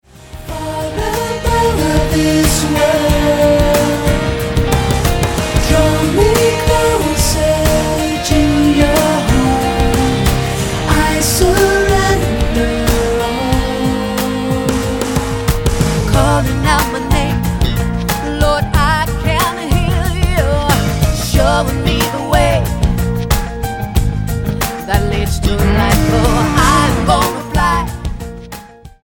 STYLE: Pop
is a moody, phased opener